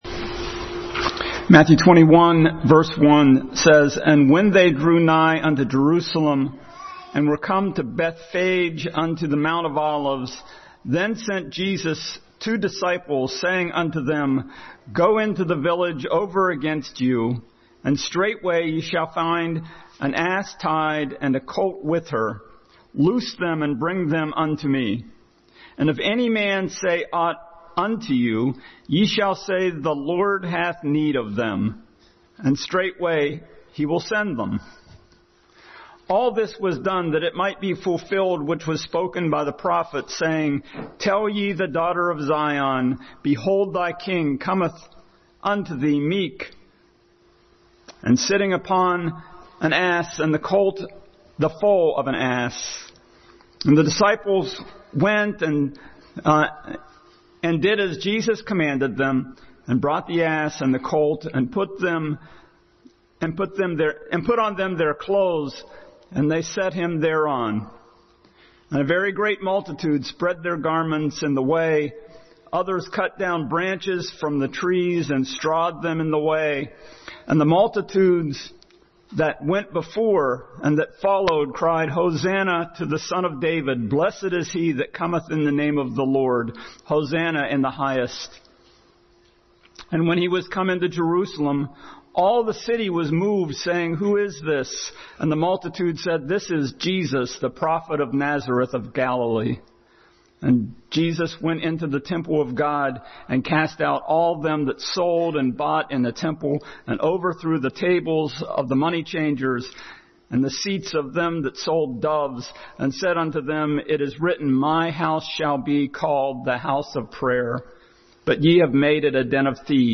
The Triumphal Entry Passage: Matthew 21:1-17, John 12:9-11, Zechariah 9:9, Luke 19:39-40, Habakkuk 2:9-11 Service Type: Sunday School